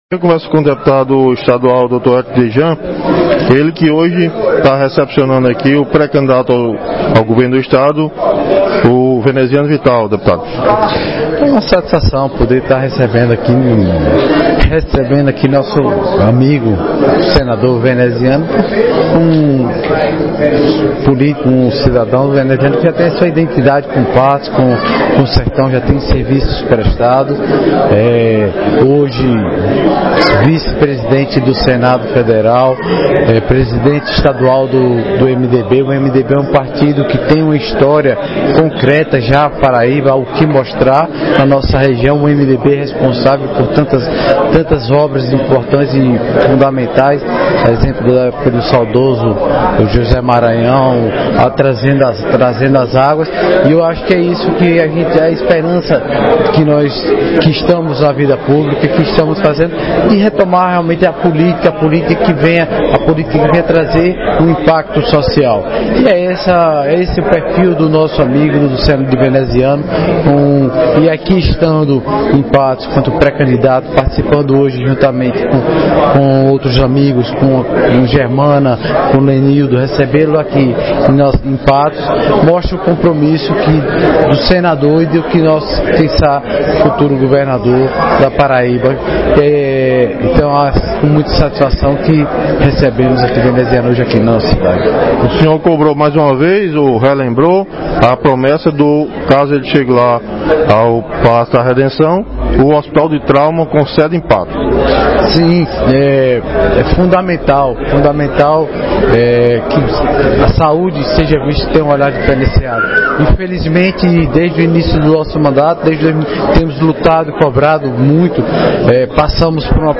Ouça o áudio de Érico Djan: